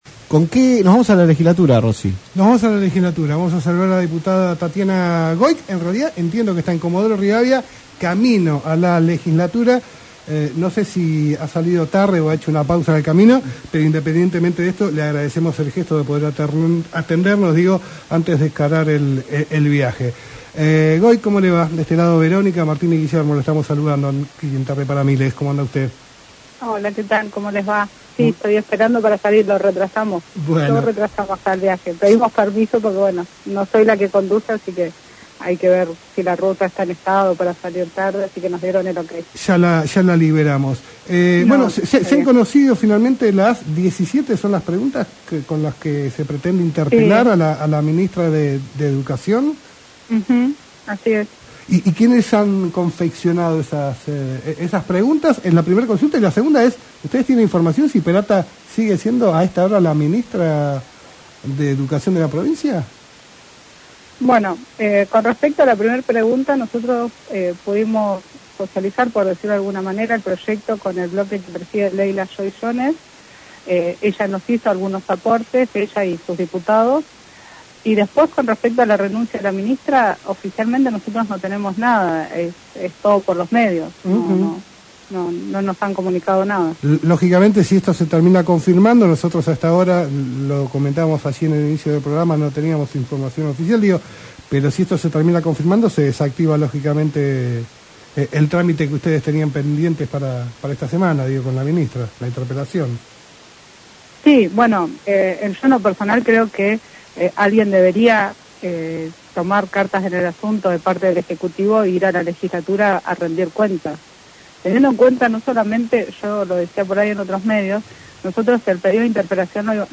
La diputada provincial Tatiana Goic estuvo presente en TPM y habló sobre el pedido de interpelación para la ministra de Educación, Florencia Perata, tras la intoxicación de alumnos y docentes por monóxido de carbono en El Maitén.